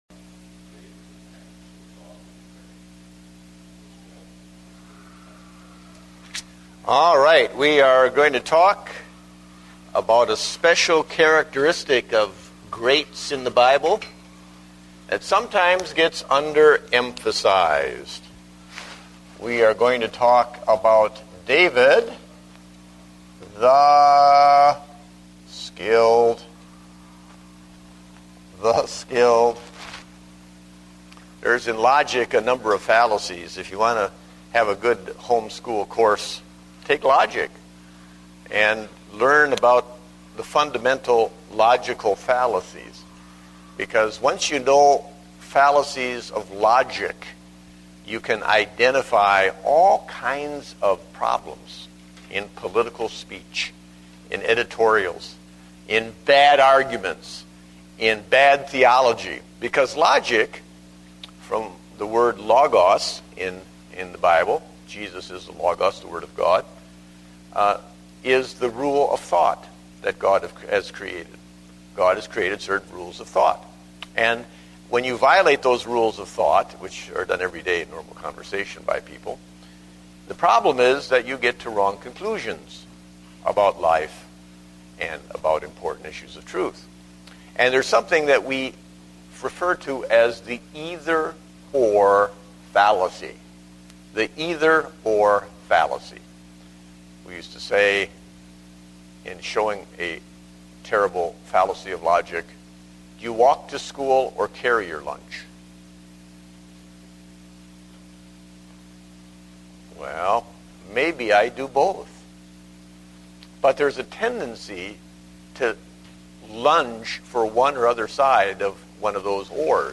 Date: October 11, 2009 (Adult Sunday School)